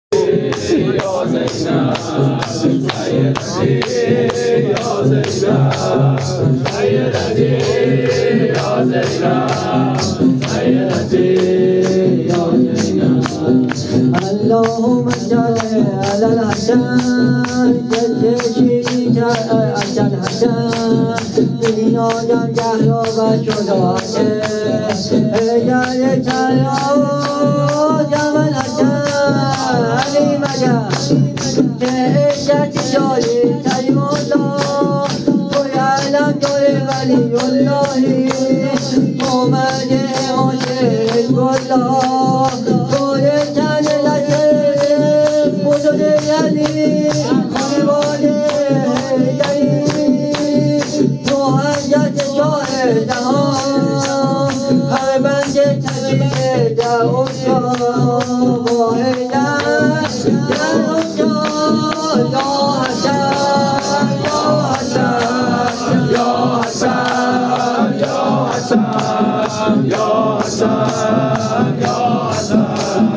شور حماسی
هیئت رقیه جان